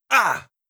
Damage Sounds
13. Damage Grunt (Male).wav